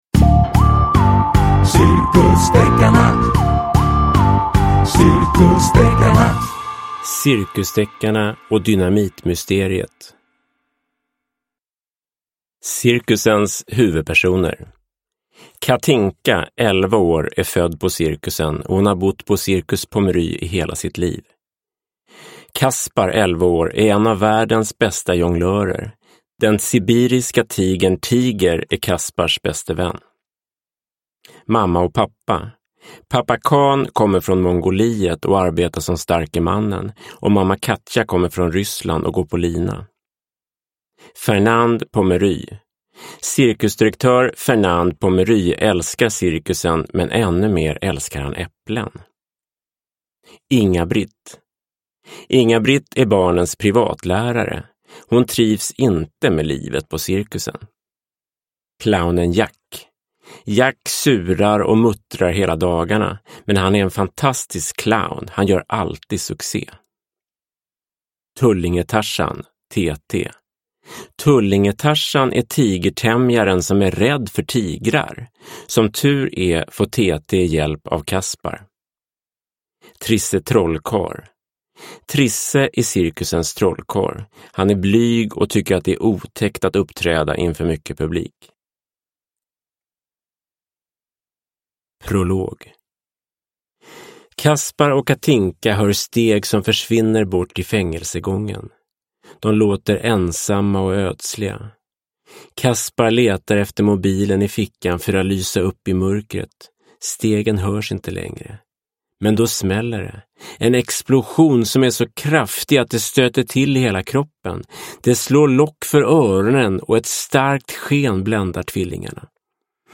Cirkusdeckarna och dynamitmysteriet – Ljudbok – Laddas ner